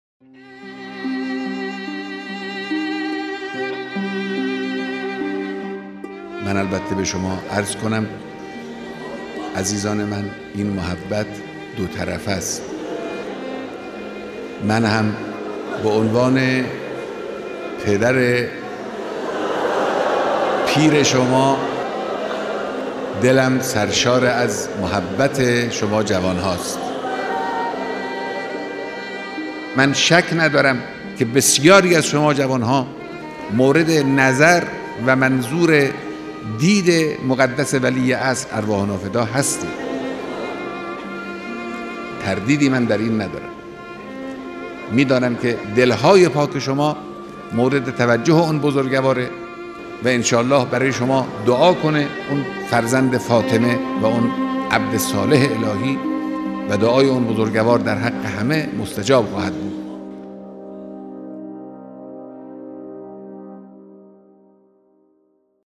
صداهنگ